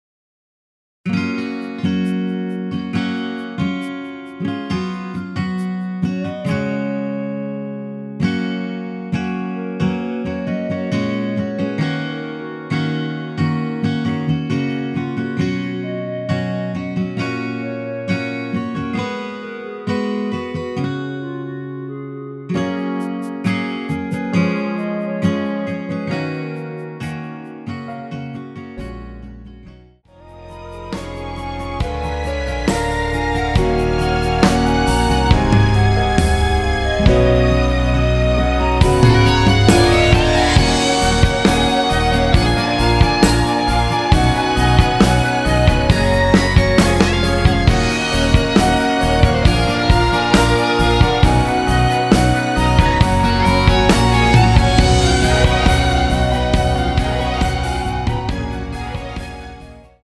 원키에서(+5)올린 (1절+후렴) 멜로디 포함된 MR입니다.(미리듣기 확인)
F#
앞부분30초, 뒷부분30초씩 편집해서 올려 드리고 있습니다.
중간에 음이 끈어지고 다시 나오는 이유는